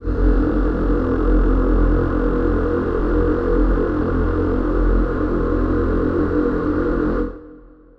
Choir Piano (Wav)